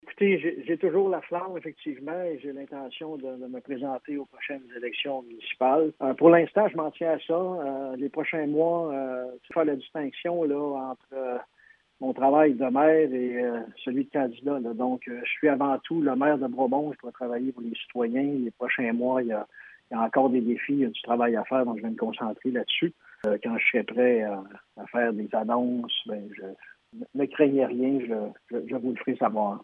On peut entendre M. Villeneuve :